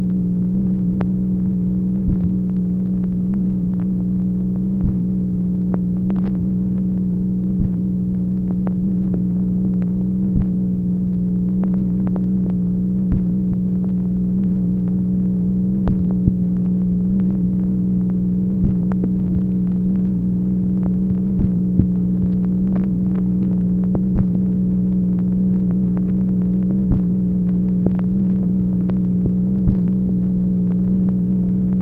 MACHINE NOISE, January 23, 1964
Secret White House Tapes